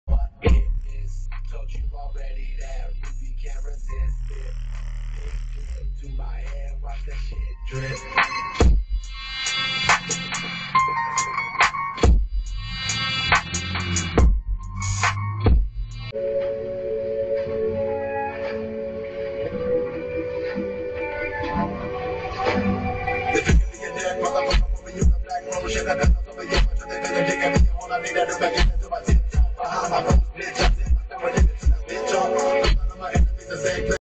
Bass Settin Off The Dash Sound Effects Free Download